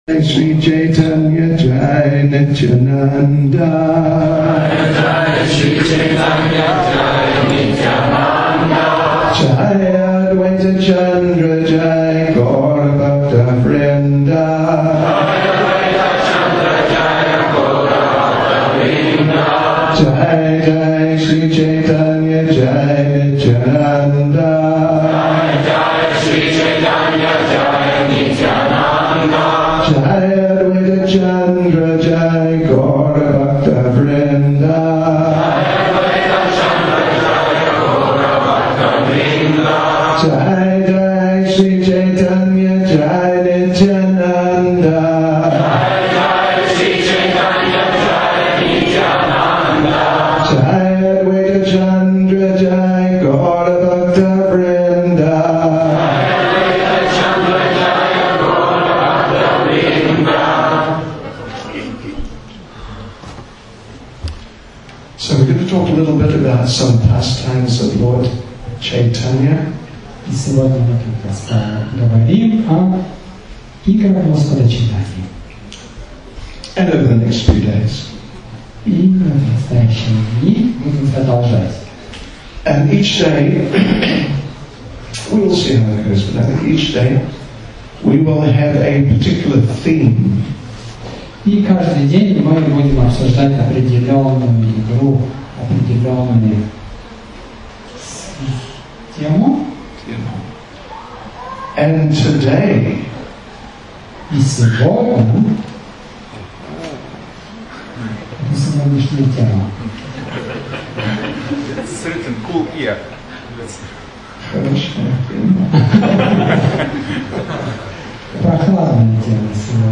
Pastimes-of-Lord-Caitanya-1,-Baltics-Summer-Festival-2011,-Lithuania.mp3